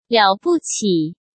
liǎo bu qǐ
liǎo bu qǐリャオブチー」になります。